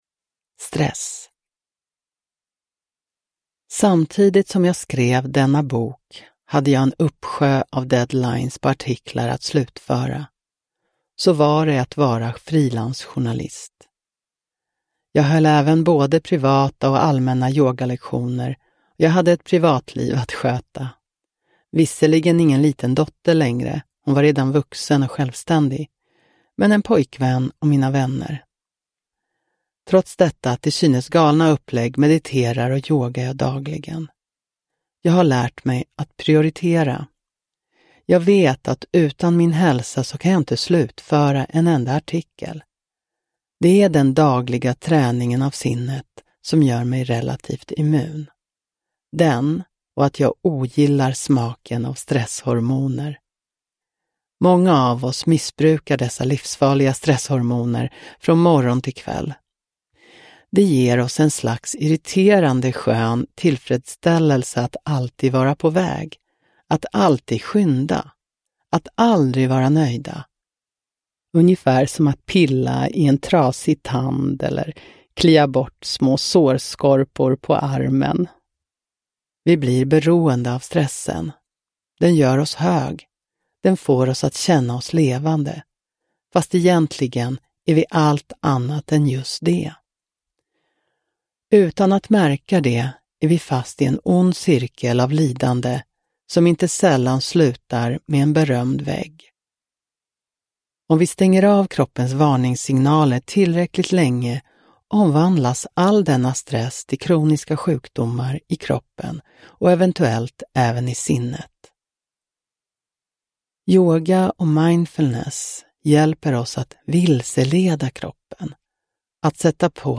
Stress – Ljudbok – Laddas ner